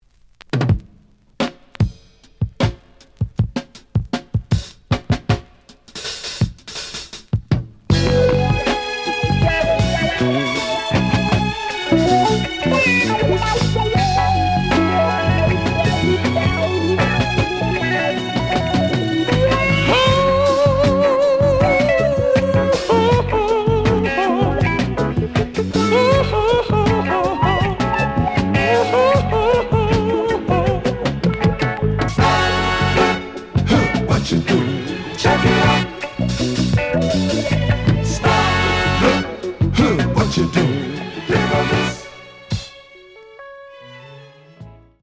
The audio sample is recorded from the actual item.
●Genre: Soul, 70's Soul
Some click noise on middle of A side, but almost good.